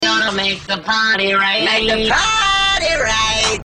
autotune